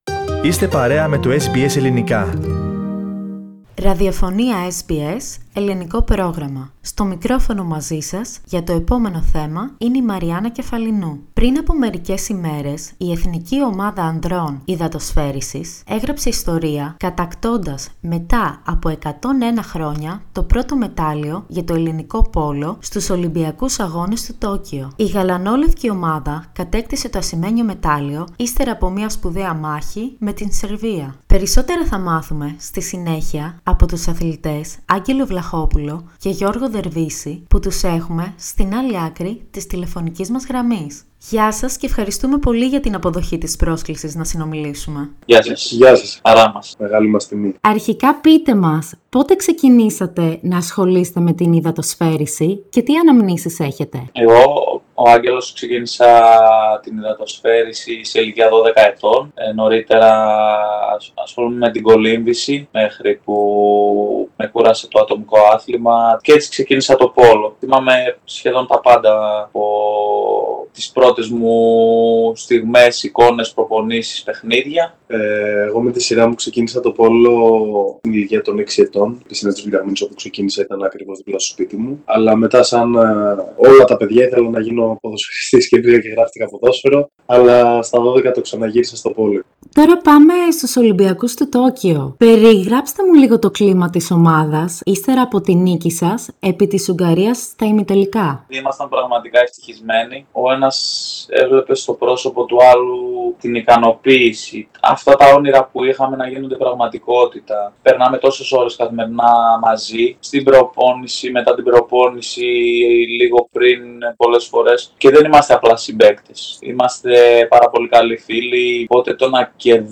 Στο πρόγραμμά μας μίλησε ο Άγγελος Βλαχόπουλος και ο Γιώργος Δερβίσης, αθλητές της υδατοσφαίρισης της Εθνικής ομάδας Ανδρών, για την εμπειρία τους στους Ολυμπιακούς Αγώνες του Τόκιο.